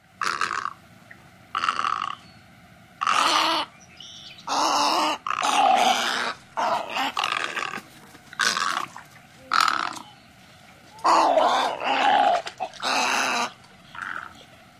雪鹭（小白鹭）叫声